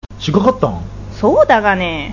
医療人のための群馬弁講座；慣用句